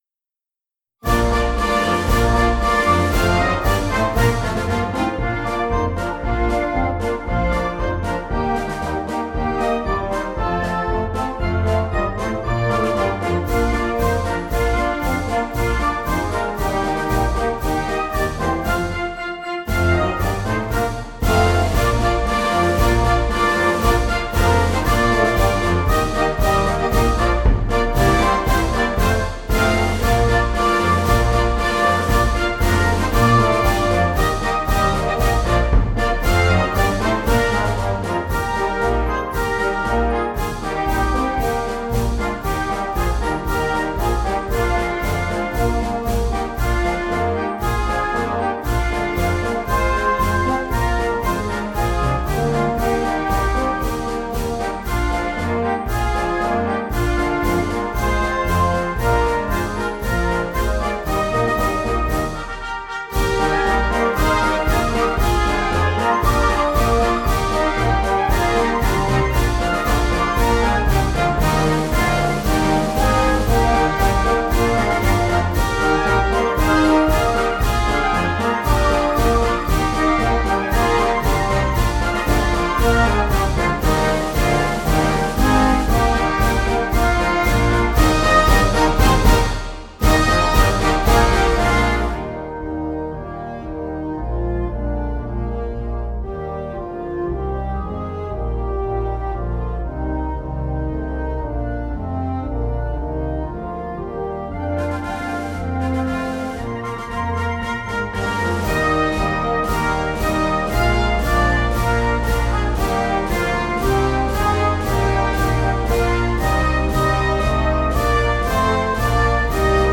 Gattung: Weihnachtsmedley für Blasorchester
Besetzung: Blasorchester